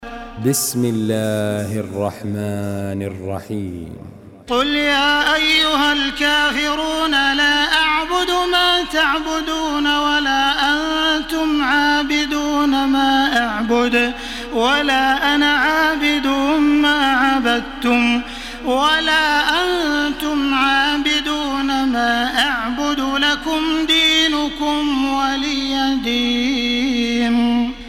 تحميل سورة الكافرون بصوت تراويح الحرم المكي 1433
مرتل